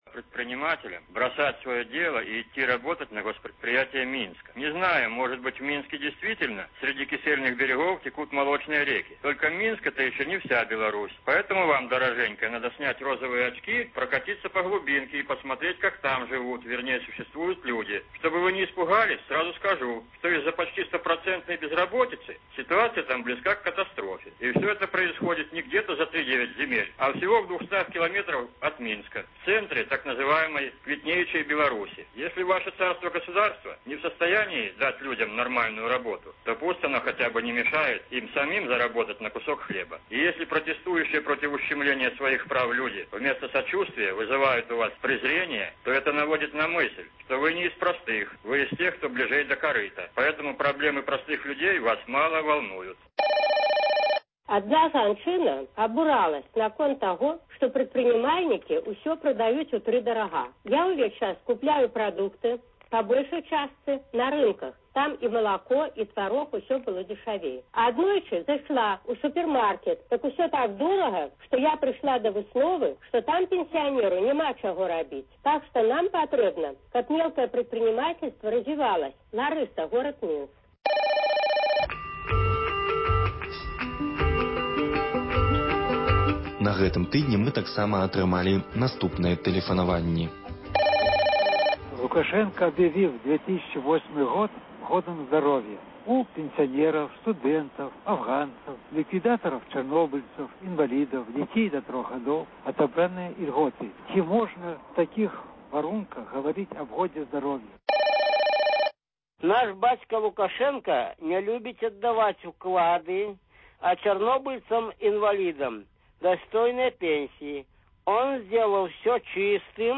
гутарыць з мастаком і літаратарам